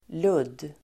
Ladda ner uttalet
Uttal: [lud:]